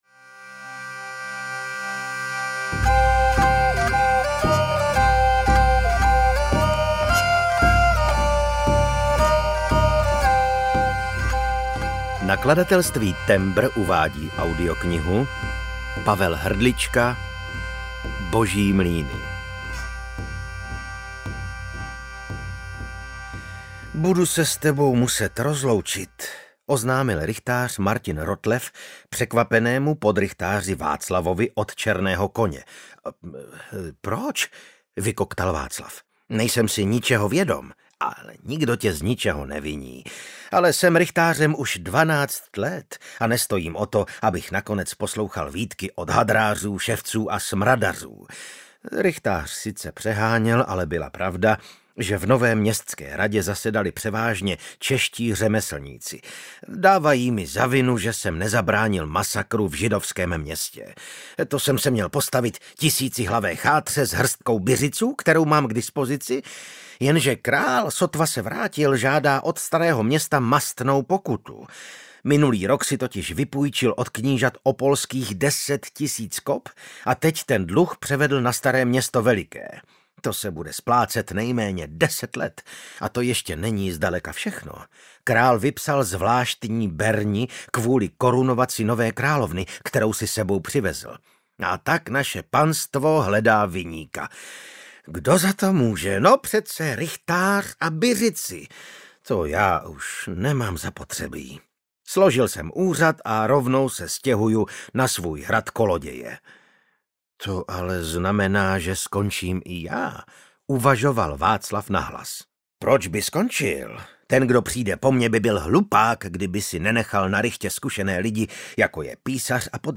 Boží mlýny audiokniha
Ukázka z knihy